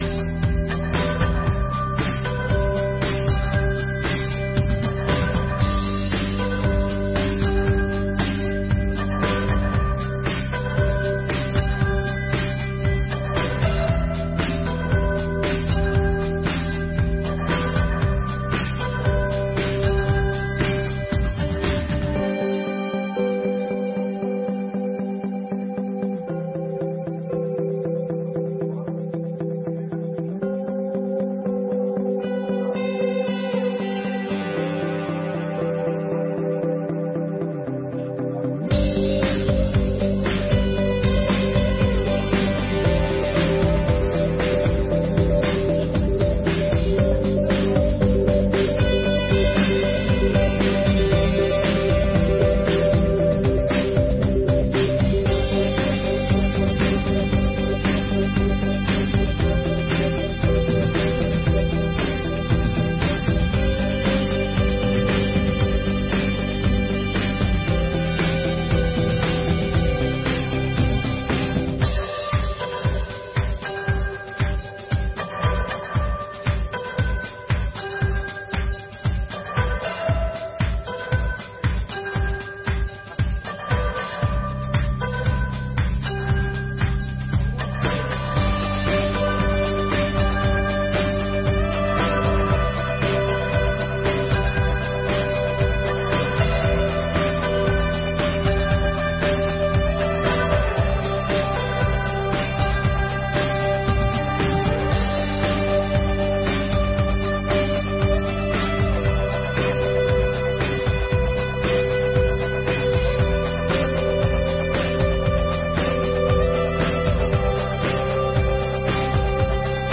30ª Sessão Ordinária de 2023